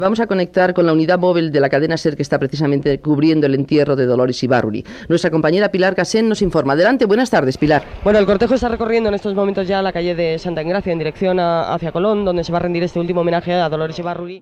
Connexió amb la unitat mòbil que segueix la comitiva funerària el dia de l'enterrament de la política Dolores Ibárruri 'La Pasionaria', a la ciutat de Madrid